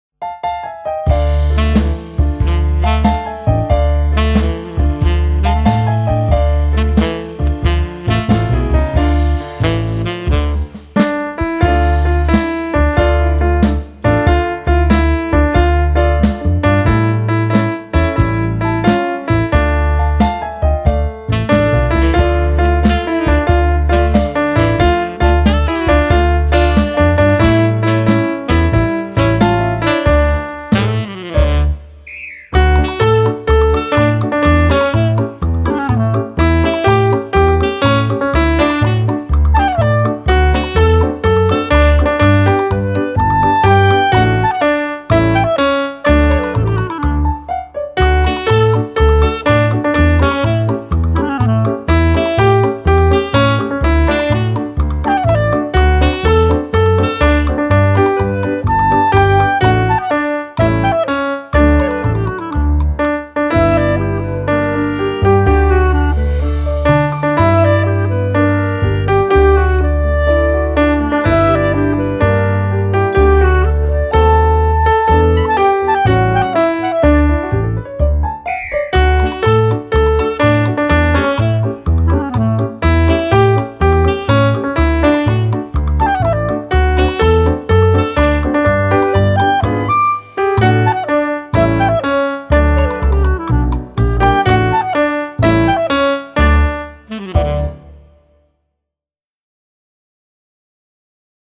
Jesienne popołudnie (wersja instrumentalna)